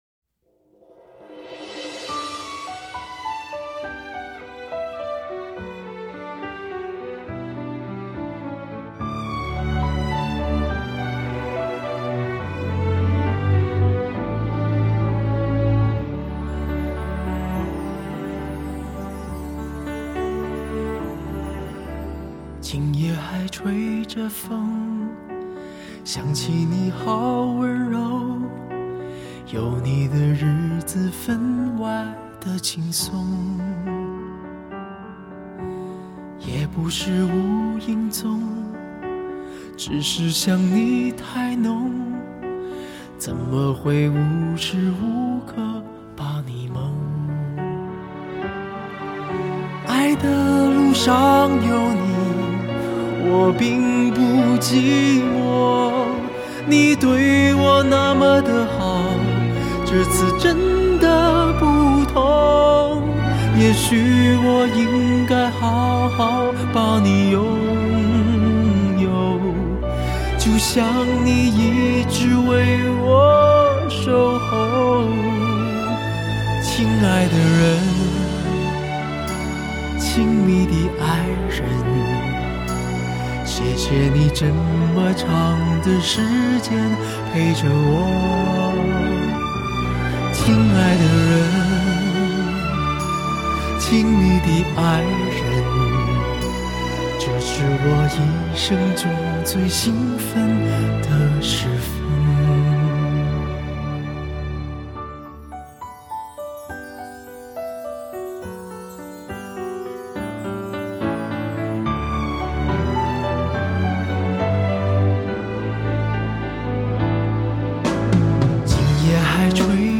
发烧录音 美国制练 留存此声岂应天上有